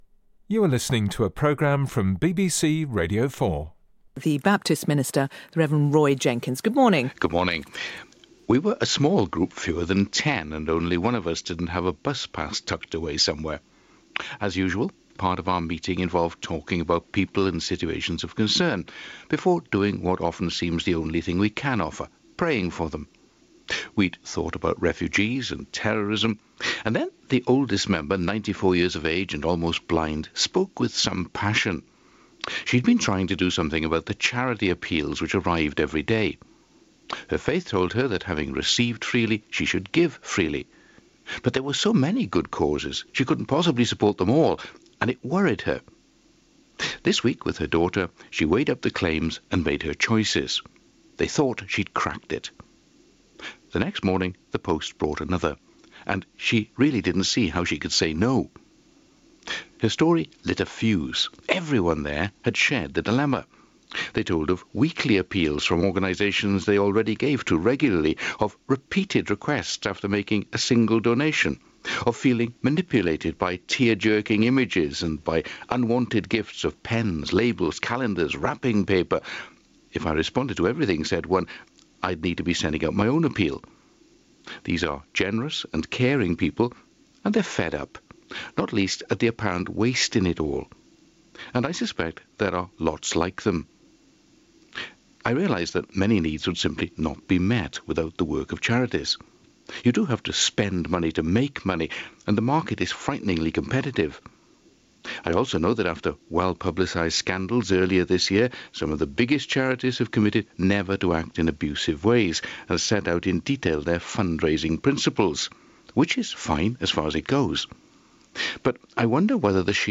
Hear a Baptist Minister’s moving tale of how over-solicitation affects his parishioners as